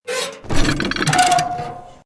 CHQ_GOON_rattle_shake.ogg